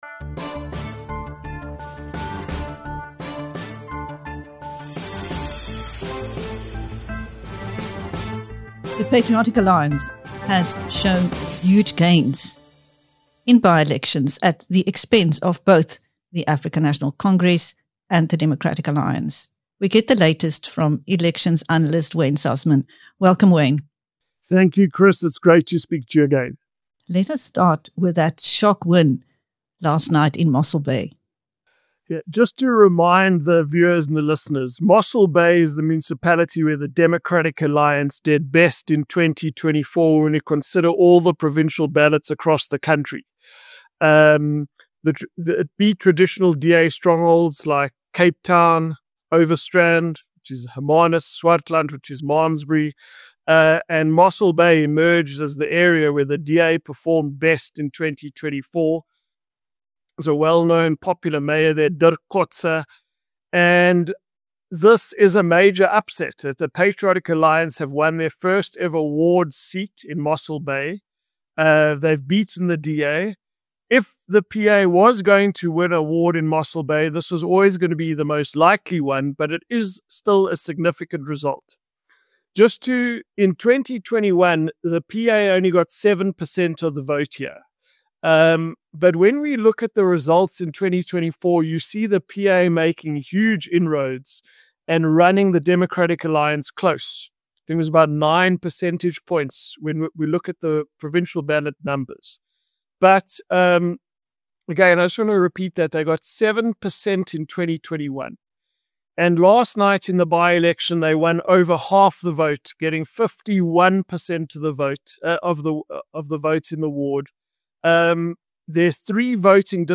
The Patriotic Alliance (PA) has shown huge gains in by-elections at the expense of both the African National Congress (ANC) and the Democratic Alliance (DA). In his latest interview with BizNews